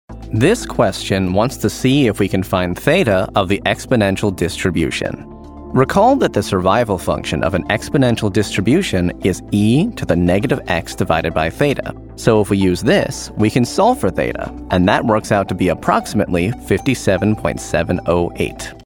Engels (Amerikaans)
Natuurlijk, Vriendelijk, Warm
E-learning